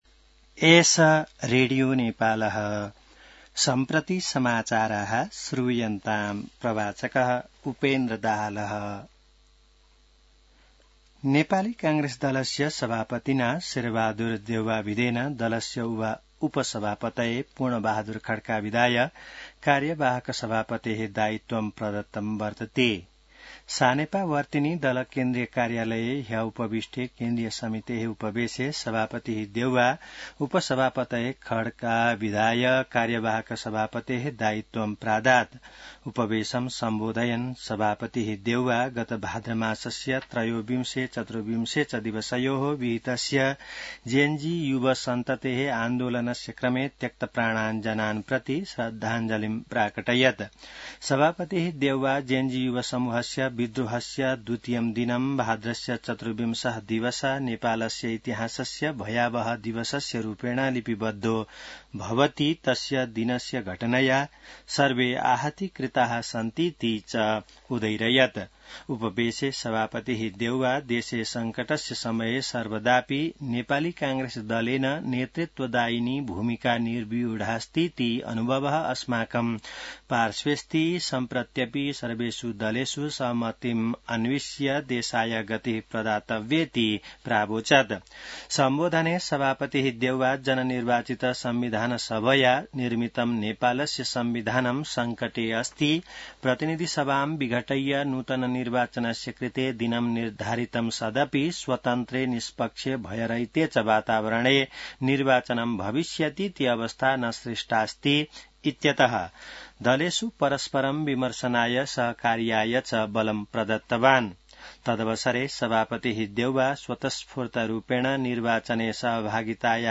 संस्कृत समाचार : २९ असोज , २०८२